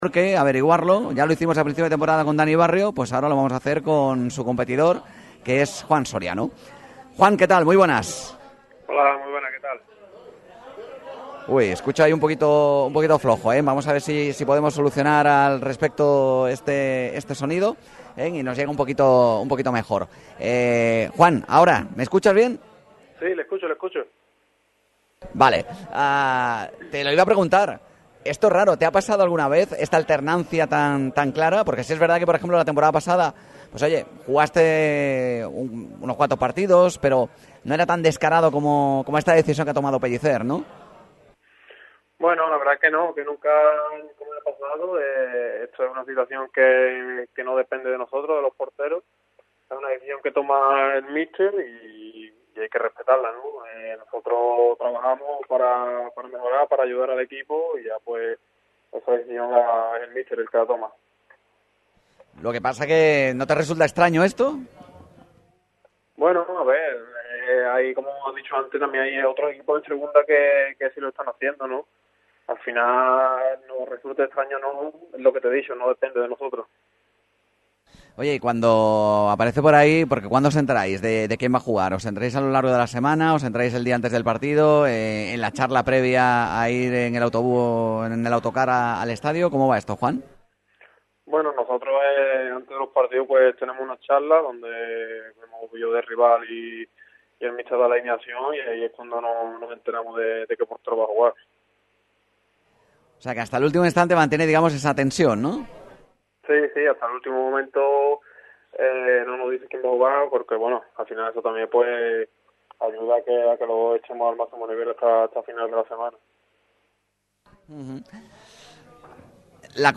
Muchos temas saltaron al aire desde la taberna La Manuela.